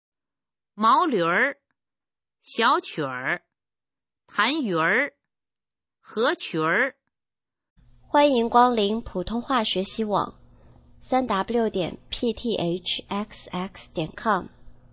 普通话水平测试用儿化词语表示范读音第15部分